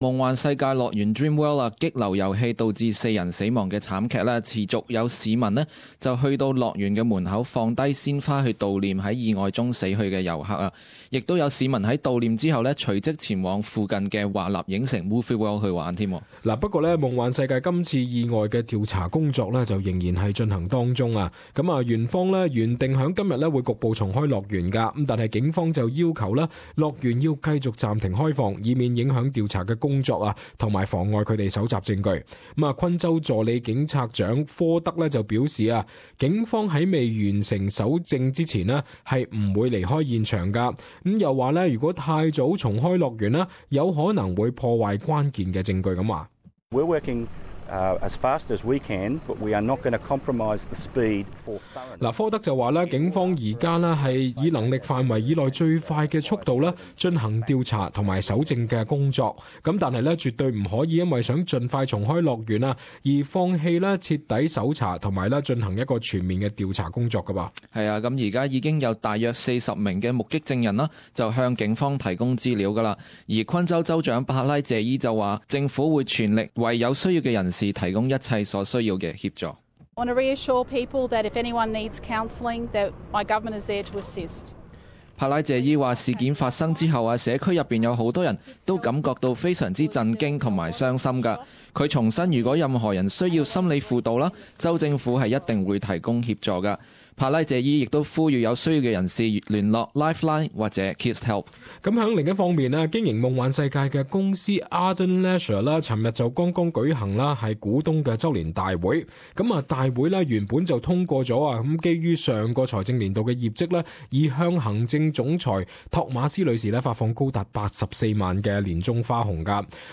【時事報導】 Dreamworld 管理層繼續受外界抨擊